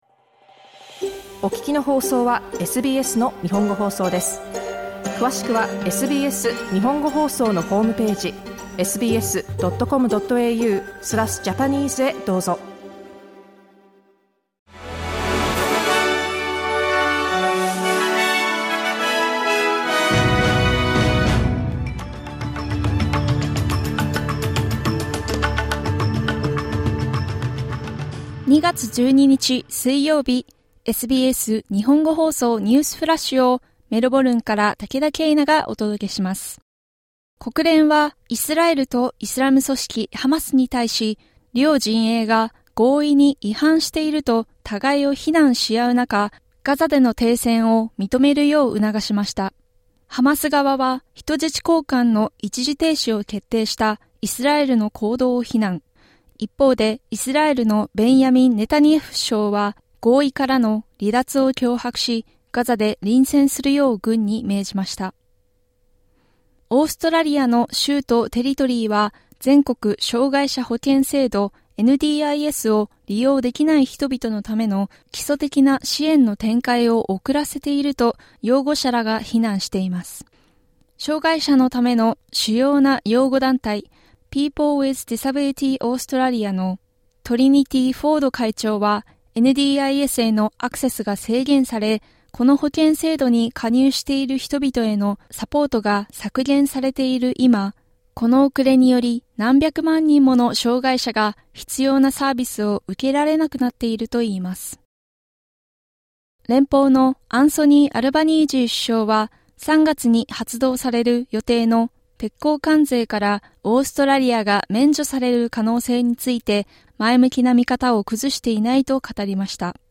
SBS日本語放送ニュースフラッシュ 2月12日水曜日